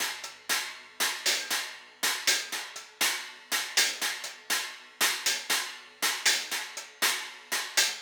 Cymbal Pattern 01.wav